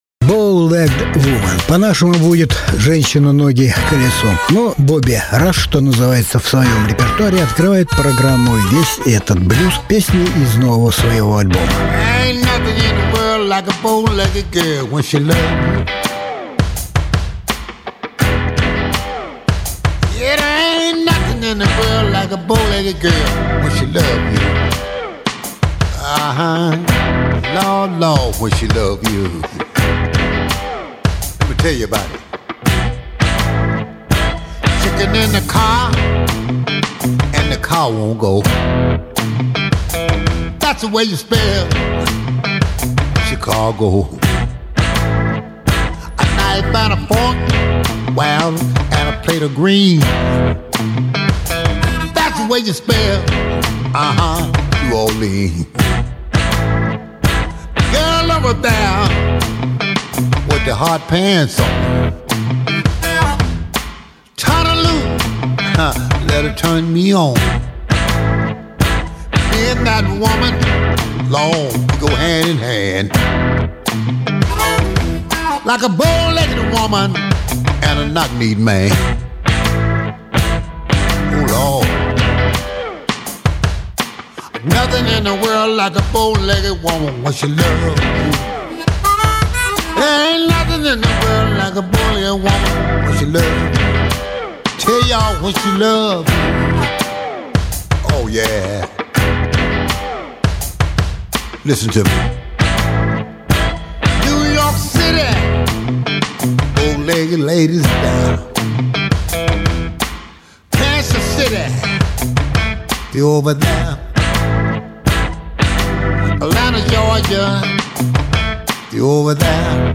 Различные альбомы Жанр: Блюзы СОДЕРЖАНИЕ 26.08.2019 Сегодня программа посвящена блюзовым новинкам этого года. 1.